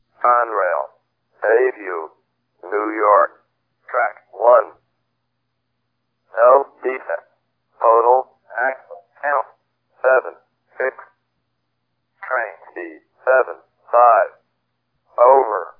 My "train" of thought is momentarily interrupted as the hot box/ dragging equipment detector is activated. The computer synthesized voice saying,